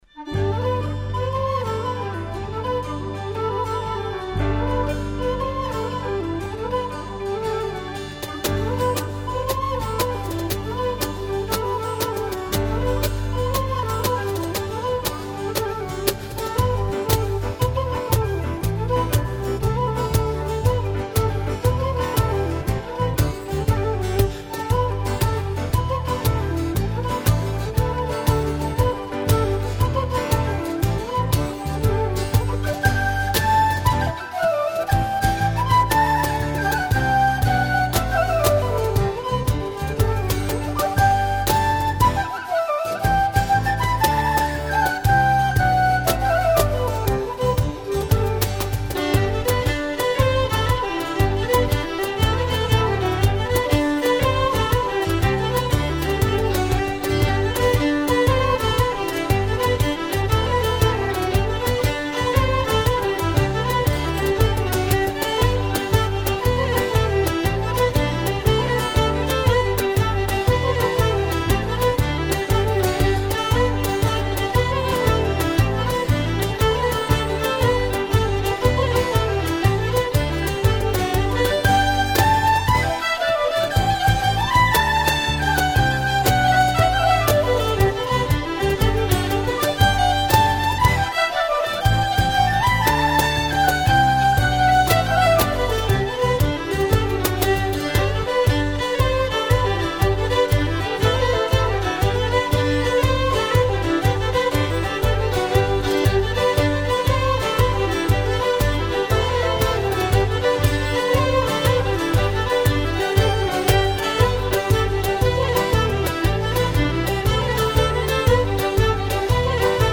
Midlands-based Celtic Ceilidh band for hire. Based in Leicester, Govannen is an experienced ceilidh/barn dance band and one of the best in the UK (ceilidh and barn dance are essentially the same thing).